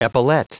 Prononciation du mot epaulet en anglais (fichier audio)
Prononciation du mot : epaulet